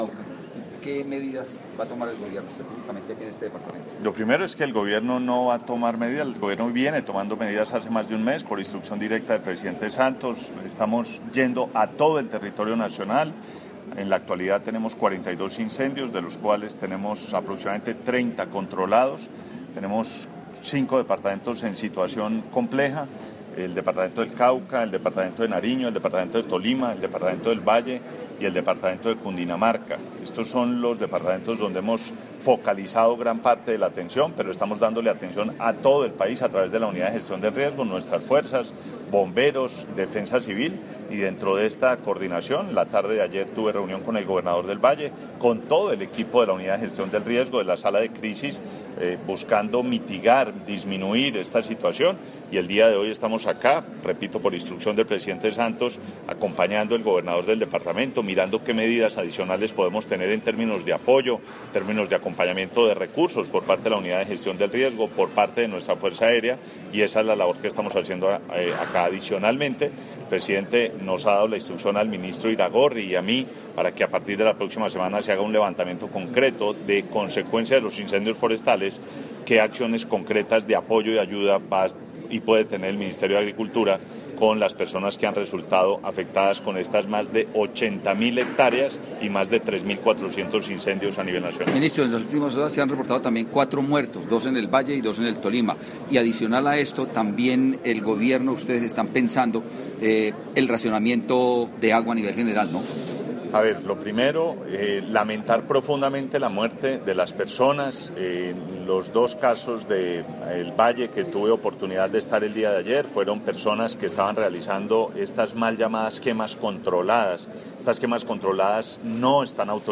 Declaraciones del Ministro de Ambiente y Desarrollo Sostenible, Gabriel Vallejo López audio